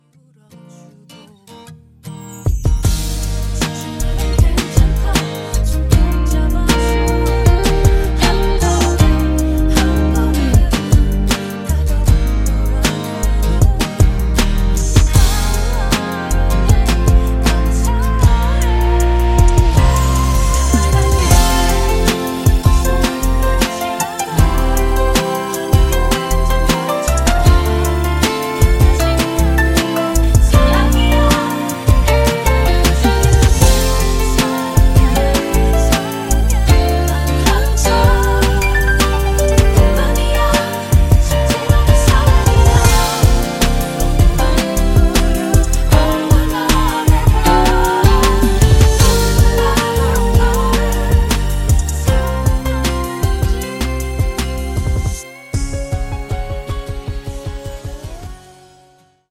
음정 -1키 3:07
장르 가요 구분 Voice MR